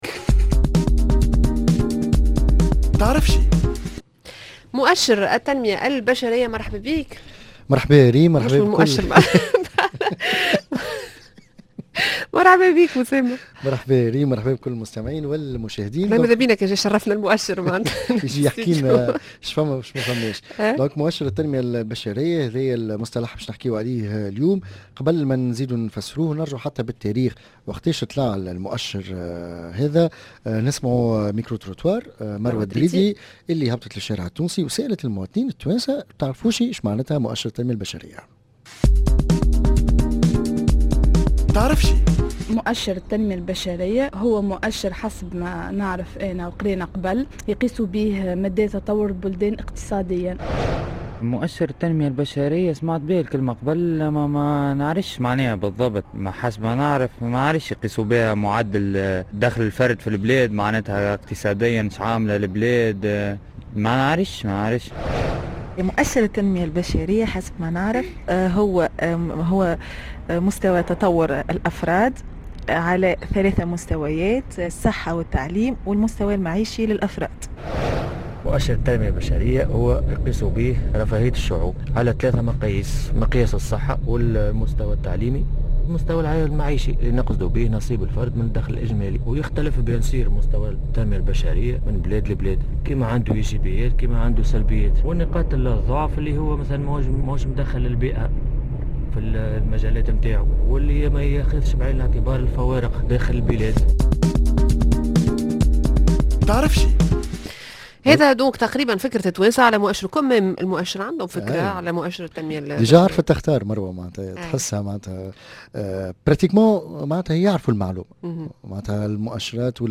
في فقرة Micro Trottoir